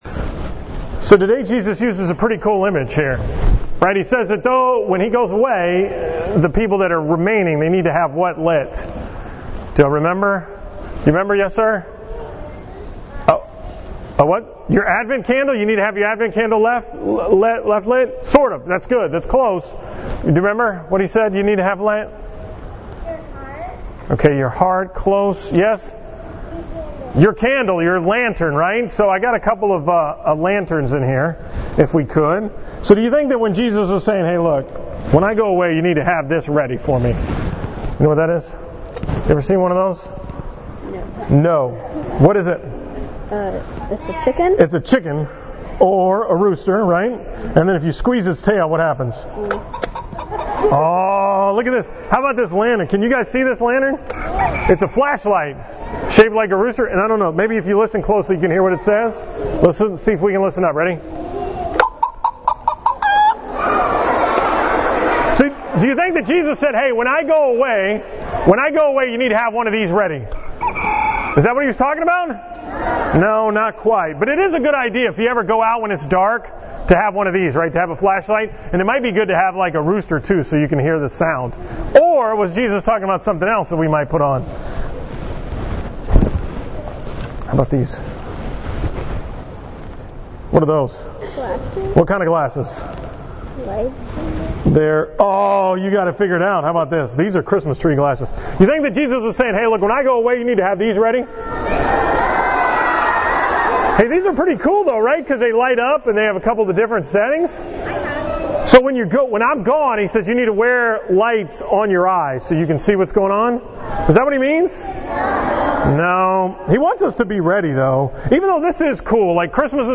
From the school Mass at John Paul II school on December 16, 2016